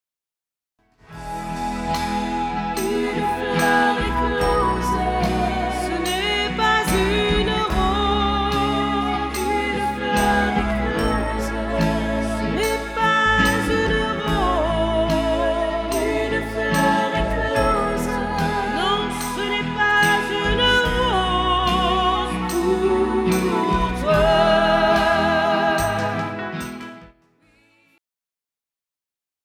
Guitares: Électrique / Acoustique
Piano / Orgue
Choriste